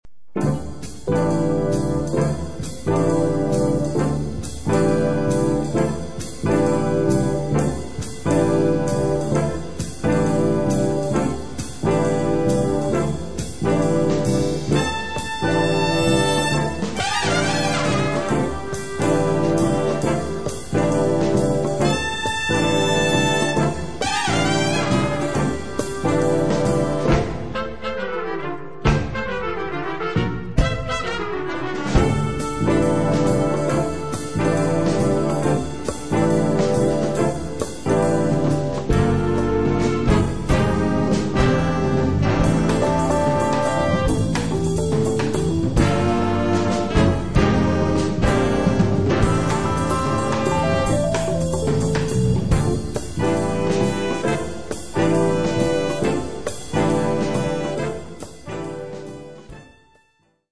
piano, Fender Rhodes
tromba, flicorno
Percussioni